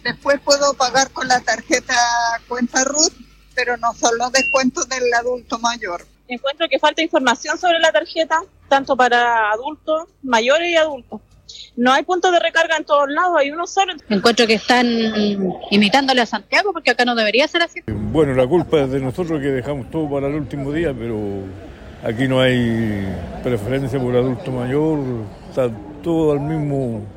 En diálogo con La Radio, algunos usuarios manifestaron su molestia y acusaron falta de información por parte de las autoridades, mientras que otros hicieron un mea culpa por “dejar todo para el último día“.